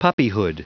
Prononciation du mot puppyhood en anglais (fichier audio)
Prononciation du mot : puppyhood